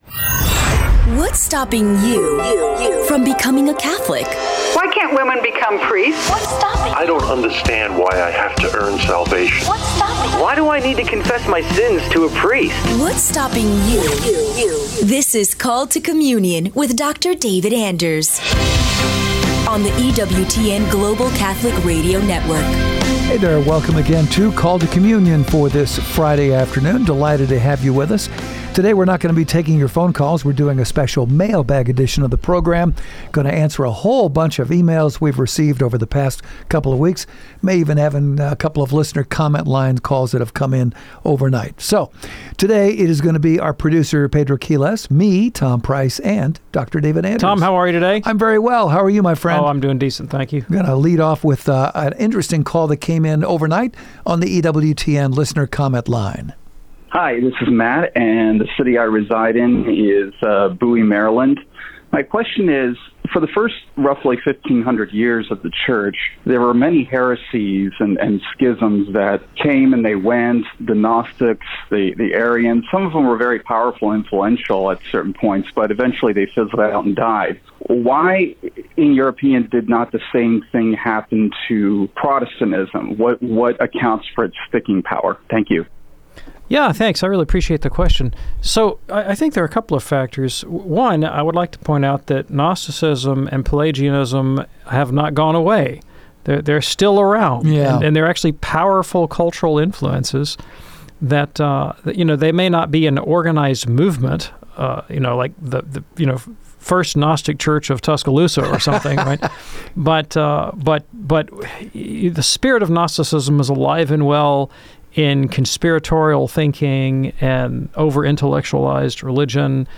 public ios_share Called to Communion chevron_right Dun Scotus and the Immaculate Conception Mar 13, 2026 A mailbag-style conversation tackling Jansenism and its suppression. They trace why Protestantism persisted in Europe and how the Old Testament canon differs.